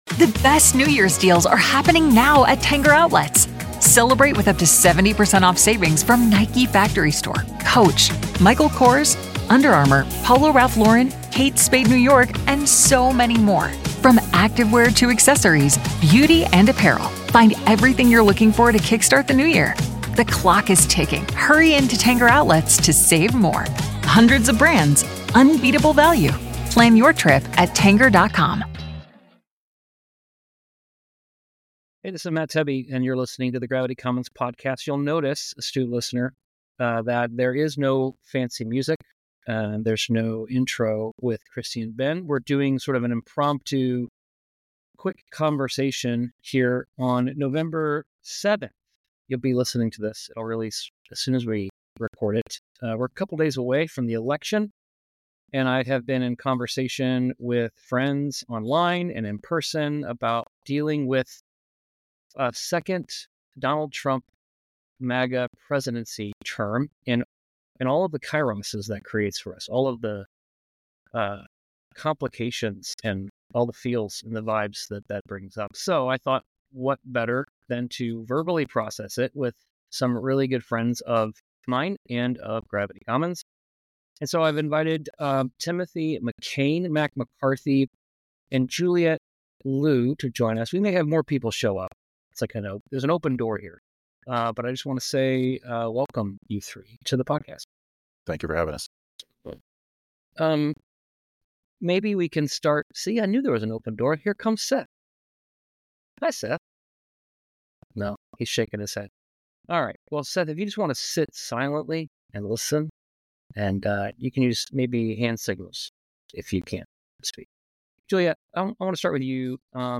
Post-Election Reflections from a Panel of Faith Leaders - Gravity Commons